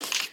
Minecraft Version Minecraft Version snapshot Latest Release | Latest Snapshot snapshot / assets / minecraft / sounds / mob / stray / step3.ogg Compare With Compare With Latest Release | Latest Snapshot
step3.ogg